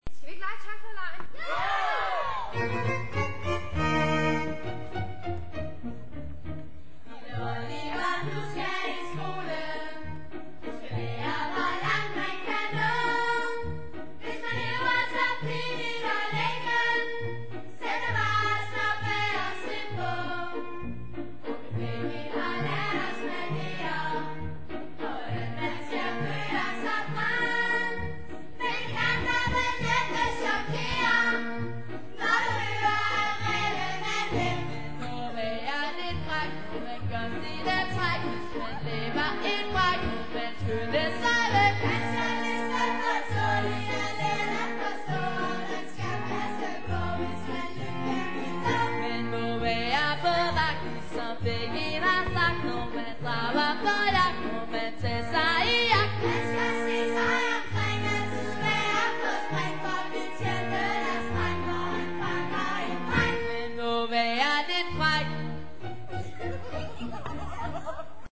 Vi hører det ene store svingende nummer efter det andet.
"live on stage!"